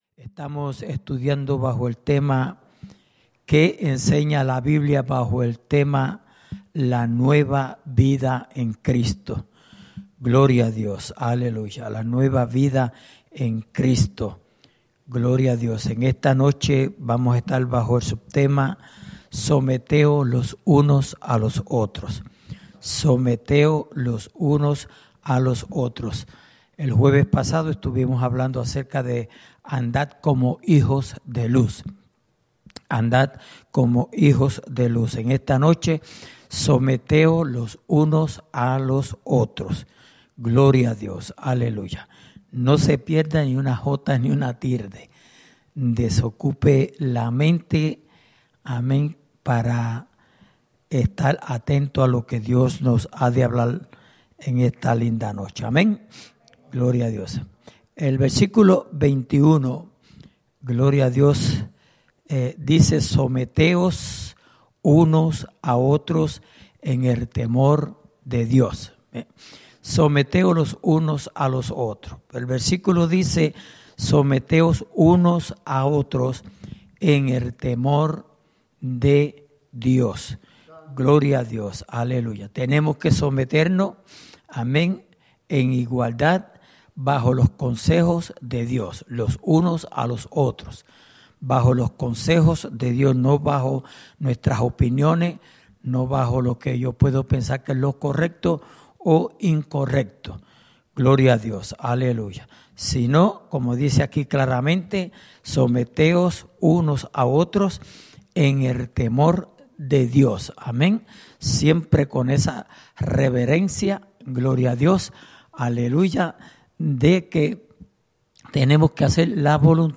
Estudio Bíblico:¿Qué Enseña La Biblia?Bajo El Tema La Nueva Vida En Cristo(Quinta Parte)